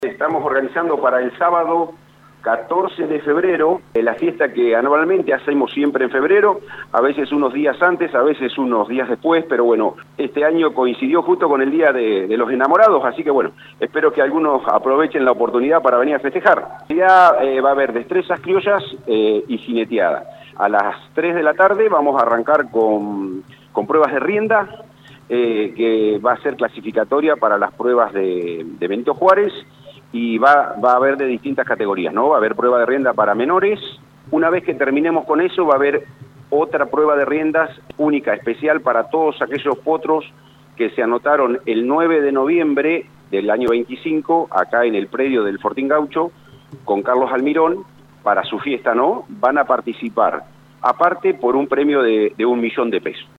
en diálogo con LU 24